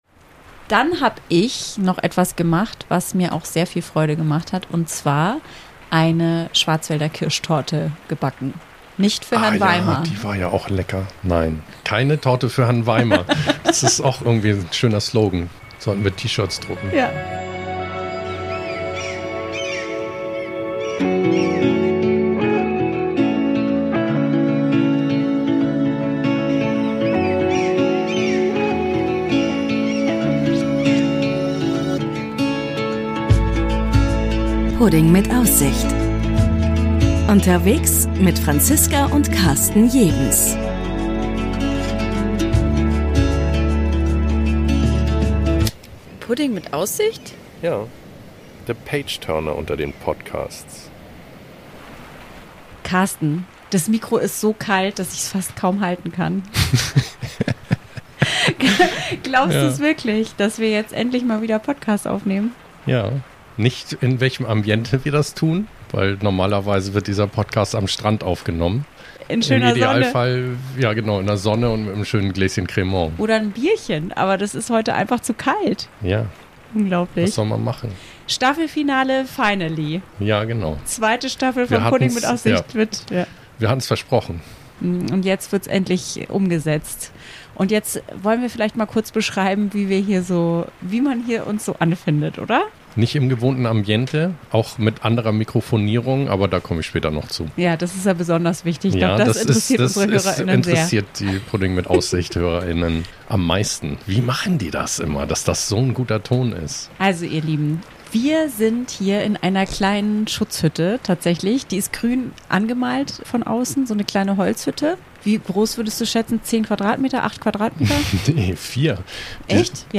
Heute ausnahmsweise mal nicht mit Meeresrauschen und Korkenknallen, sondern mit Windgeheul und Regengetrommel. Auf unserer Lieblingsinsel haben wir in einer kleinen Hütte Schutz gefunden und nochmal Revue passieren lassen, was seit der letzten Folge im August 25 noch alles passiert ist. Von neuen Romanen, Schwarzwälderkirsch und Regalkontrolle bis Rätselhäkeln, Spurenlesen und Vorhangdesign ist alles dabei und weil es eine kurze Folge ist, kommt sie gleich im Doppelpack mit der ersten Folge der dritten Staffel.